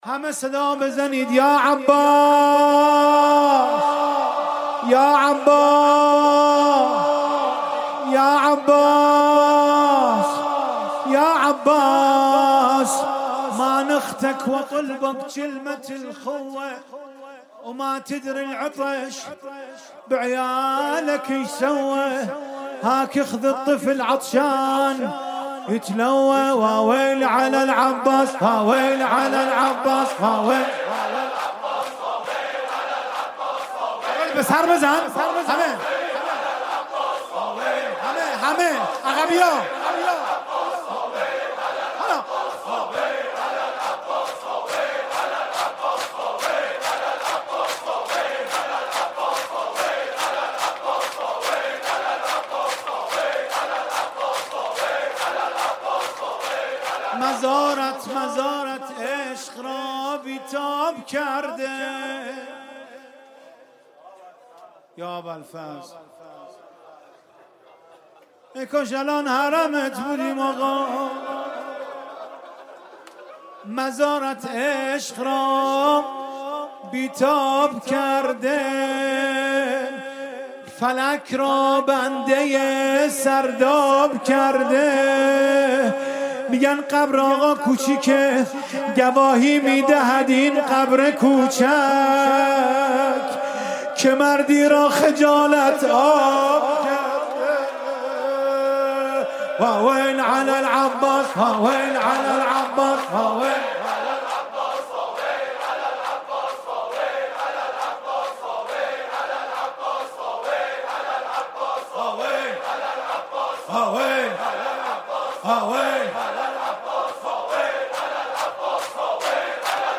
شب تاسوعا محرم 96 - مصباح الهدی - شور - واویل علی العباس